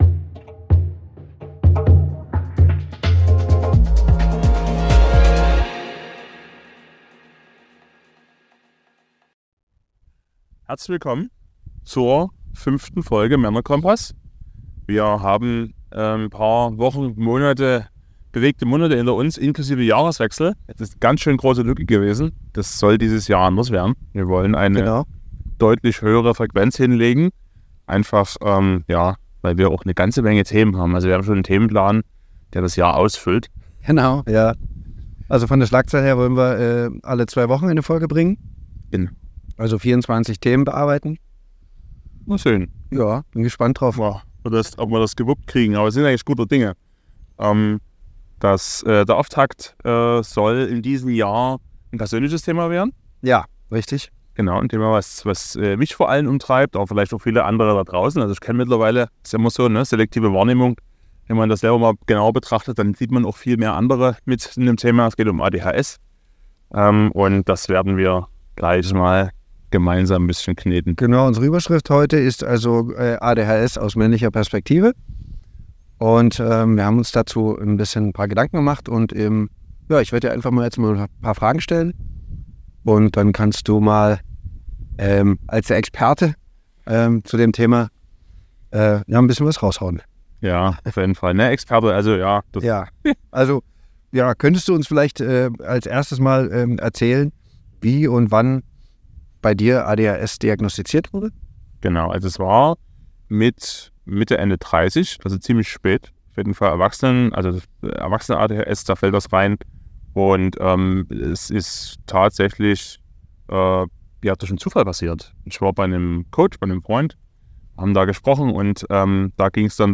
In dieser Interview-Folge schauen wir auf ein persönliches und sehr spezielles Thema